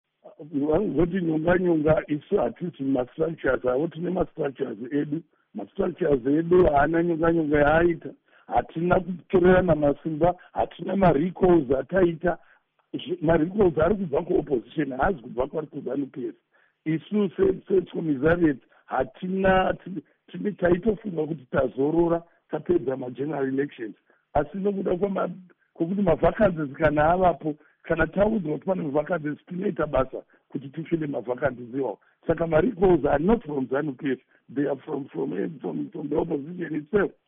Mashoko aVaMike Bimha